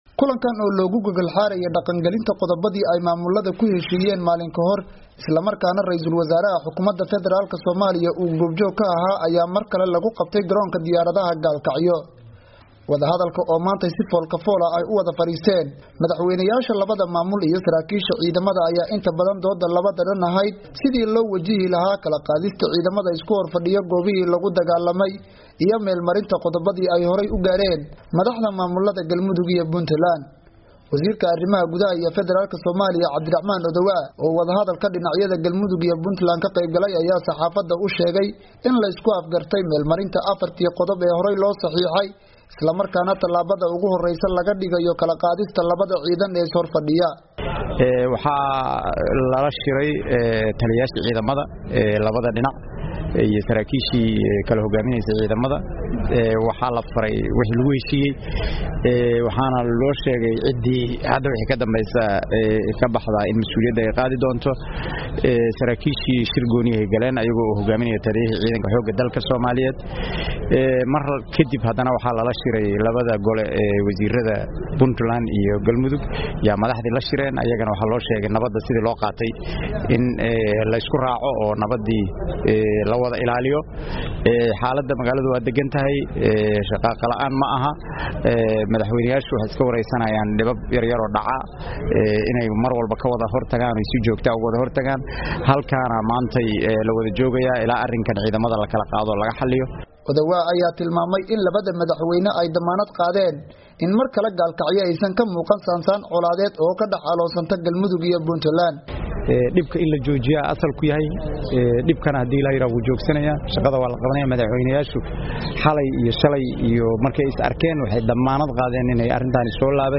Warbixin: Xaaladda Gaalkacyo